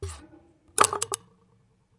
描述：记录的刀片声音。
Tag: 刀片声 单击 打击乐器 录音 毛刺 叶片 振动 现场录音 拍摄 声音